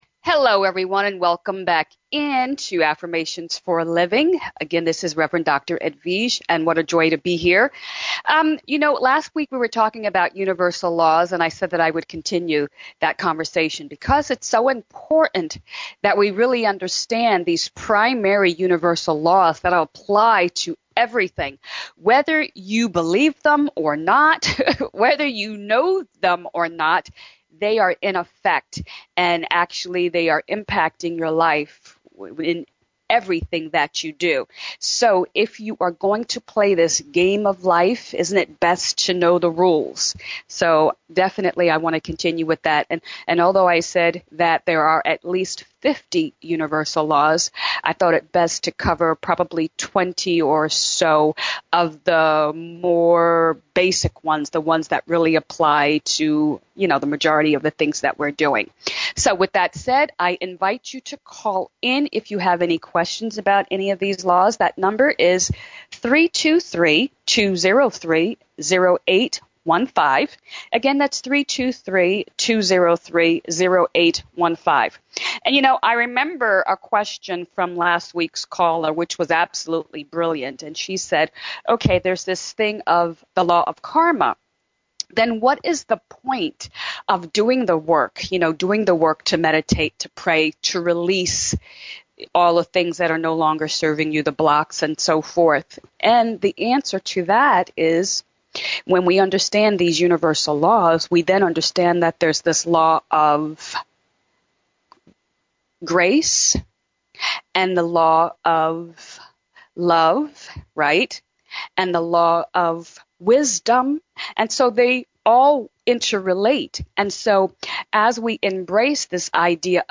Talk Show Episode, Audio Podcast, UNIVERSAL LAWS II and Master Principles Behind the Universe and Nature on , show guests , about Universe,Nature,Knowing,Master,Lifestyle, categorized as Earth & Space,Philosophy,Physics & Metaphysics,Spiritual,Psychic & Intuitive